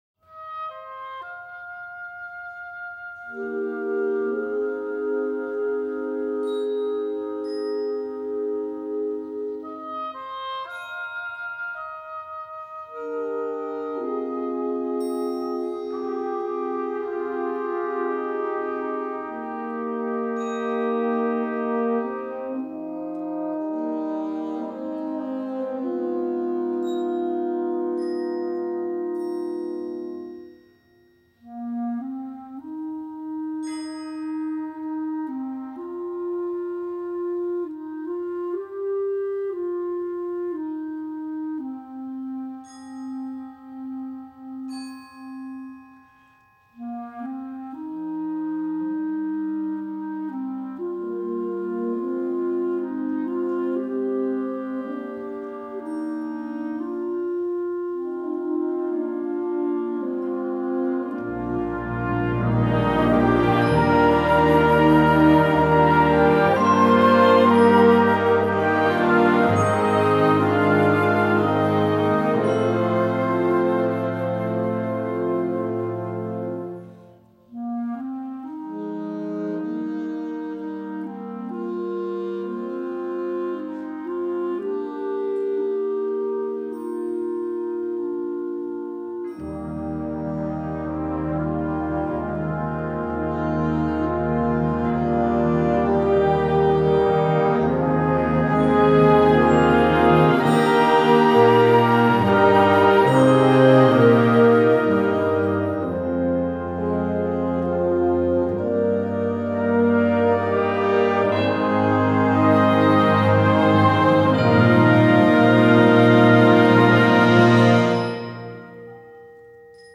Gattung: Konzertwerk für Jugendblasorchester
Besetzung: Blasorchester